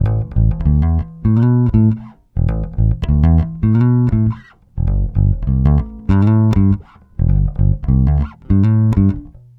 Bass 33.wav